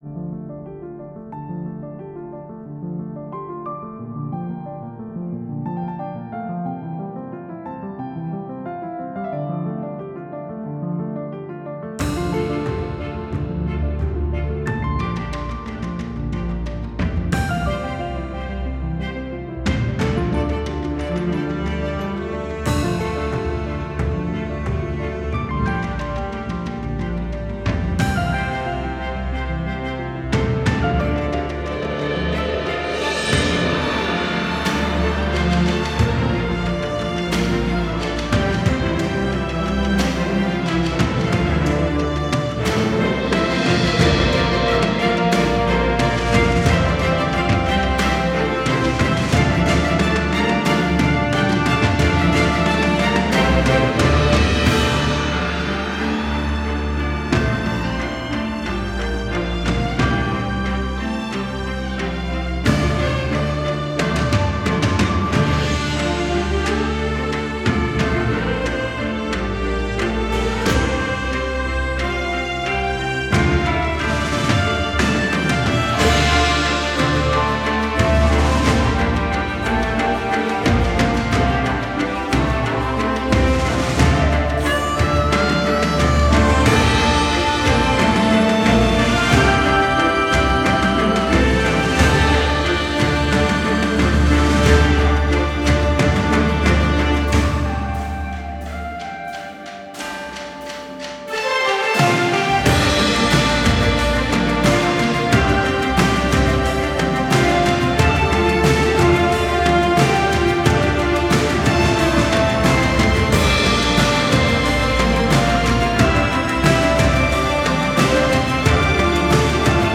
Давят на уши треки
Это как бы немножко прямо противоположные вещи Послушал первый трек - не хватает низа явно.